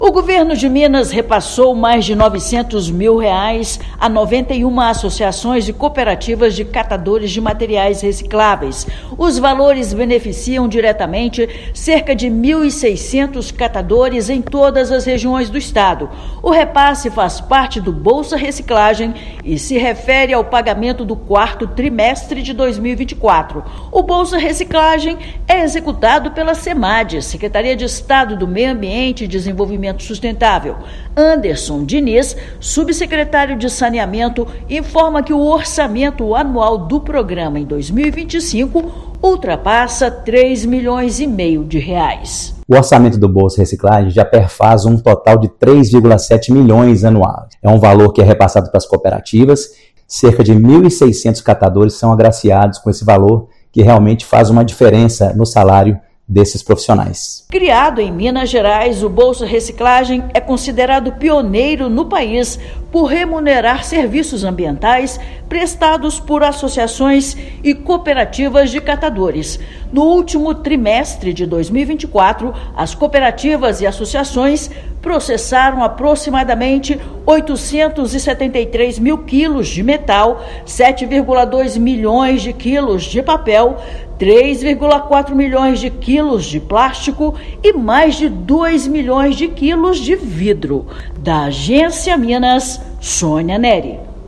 Orçamento anual em 2025 já soma R$ 3,6 milhões. Ouça matéria de rádio.